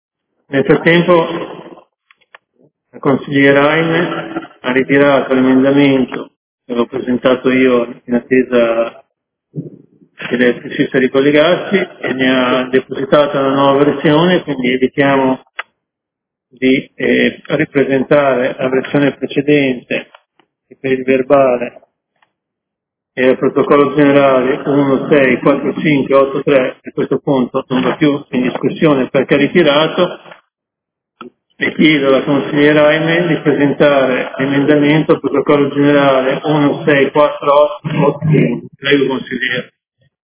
Presidente